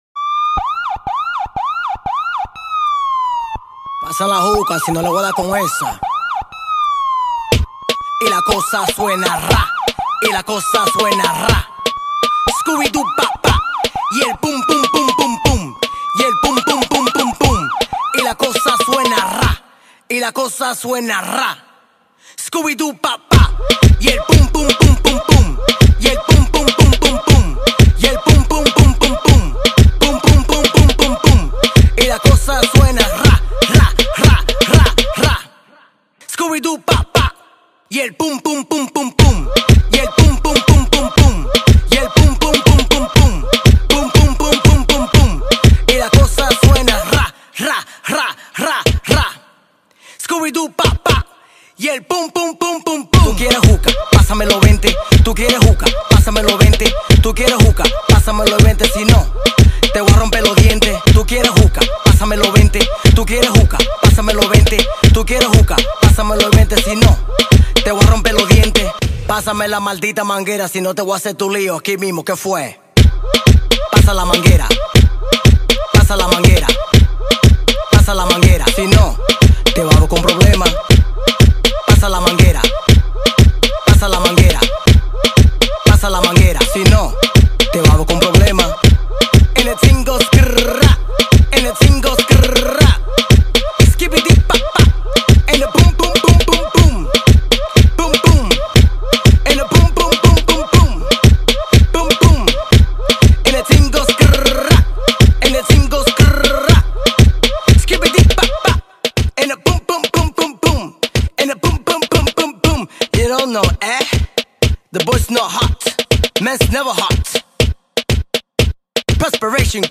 آهنگ پلیسی شوتی سیستمی لاتی مخصوص ماشین